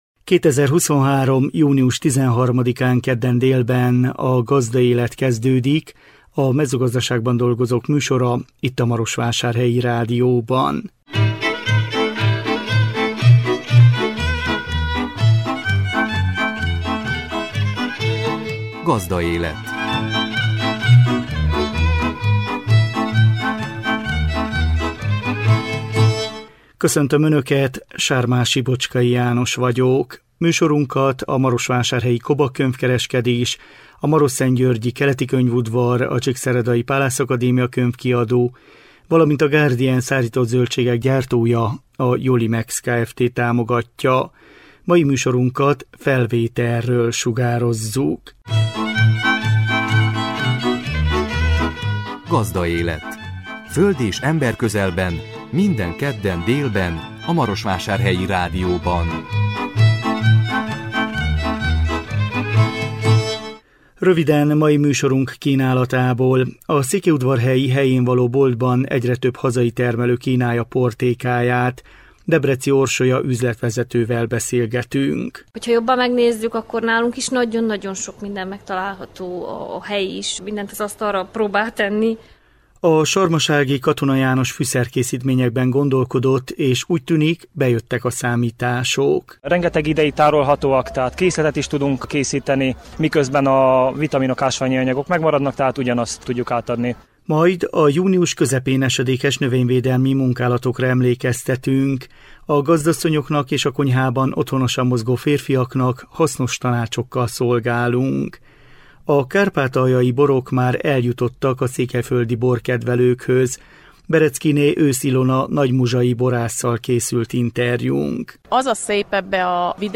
Nála jártunk mikrofonunkkal.